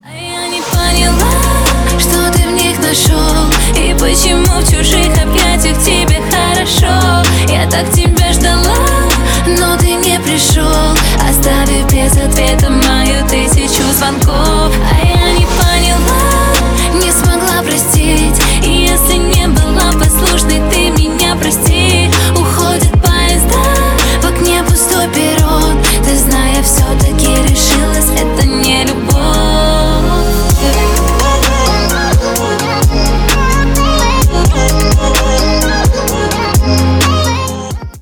грустные
поп